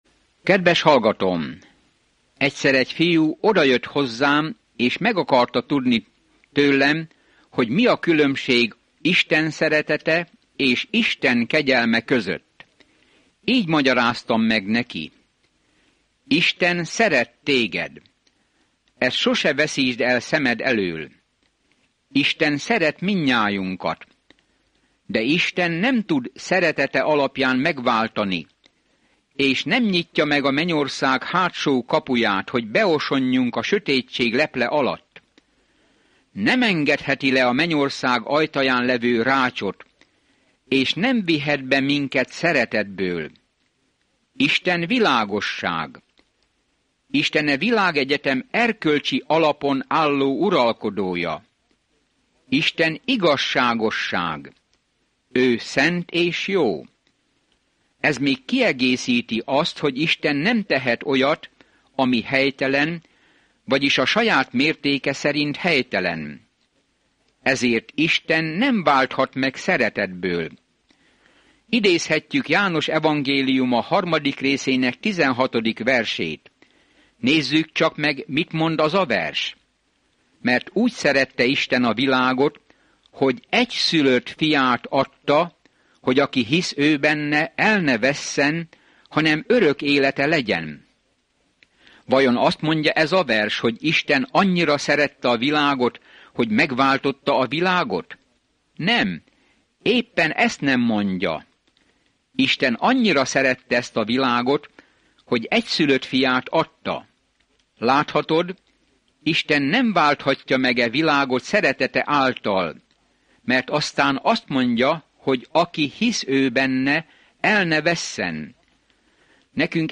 Szentírás Efezus 2:7-12 Nap 10 Olvasóterv elkezdése Nap 12 A tervről Az efézusiakhoz írt levél elmagyarázza, hogyan kell Isten kegyelmében, békéjében és szeretetében járni, a csodálatos magasságokból, hogy mit akar Isten gyermekei számára. Napi utazás az efézusi levélben, miközben hallgatod a hangos tanulmányt, és olvasol válogatott verseket Isten szavából.